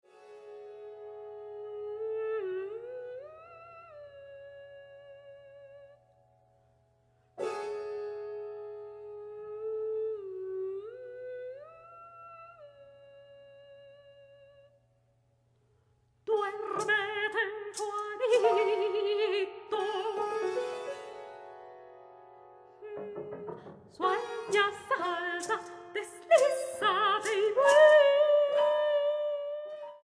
mezzosoprano
pianista.
Grabado en la Sala Nezahualcóyotl